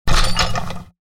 جلوه های صوتی
دانلود صدای ربات 76 از ساعد نیوز با لینک مستقیم و کیفیت بالا